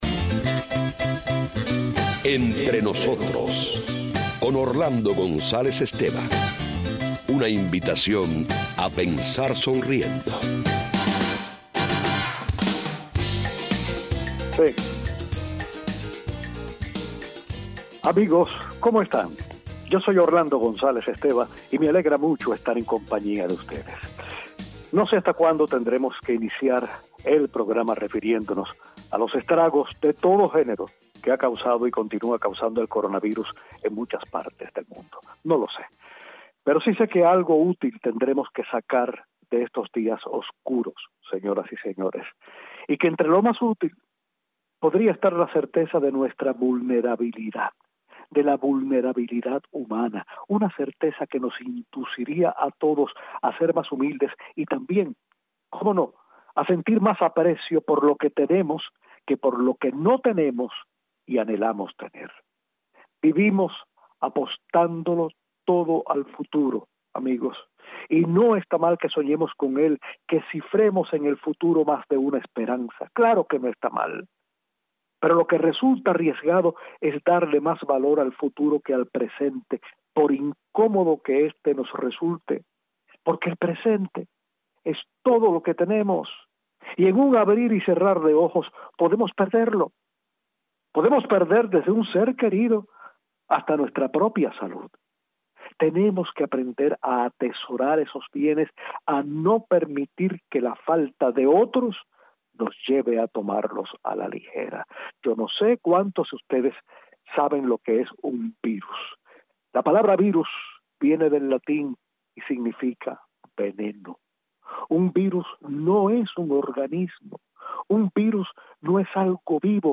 Hoy terminamos de leer el testimonio de Leslie Jamison, la escritora norteamericana que contrajo el coronavirus, y comenzamos a leer la carta de una hija a su padre médico, muerto por la misma enfermedad.